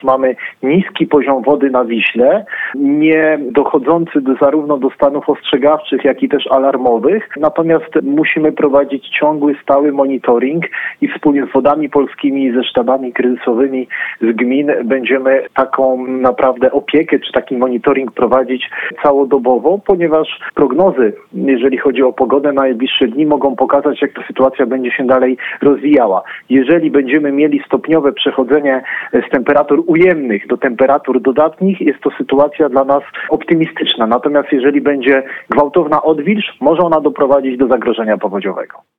– przekazał nam Starosta Płocki Sylwester Ziemkiewicz.